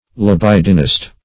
Search Result for " libidinist" : The Collaborative International Dictionary of English v.0.48: Libidinist \Li*bid"i*nist\ (l[i^]*b[i^]d"[i^]*n[i^]st), n. [See Libidinous .] One given to lewdness.